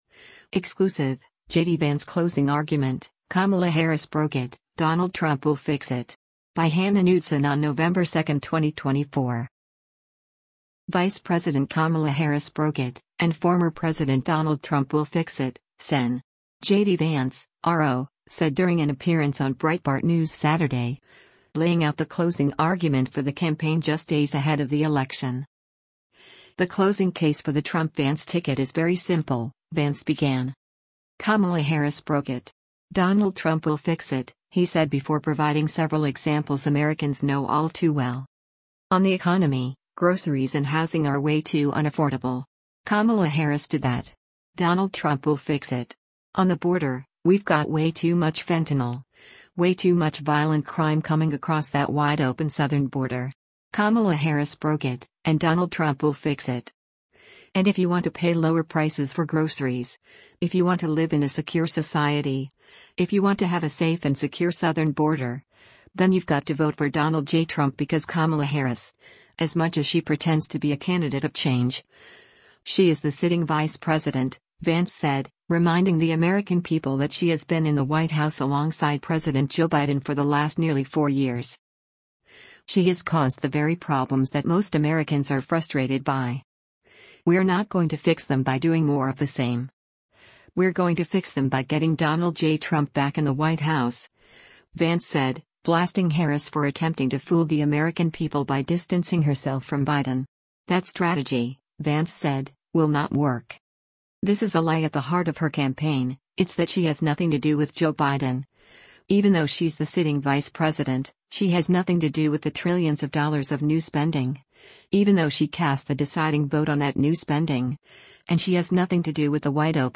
Vice President Kamala Harris broke it, and former President Donald Trump will fix it, Sen. JD Vance (R-OH) said during an appearance on Breitbart News Saturday, laying out the closing argument for the campaign just days ahead of the election.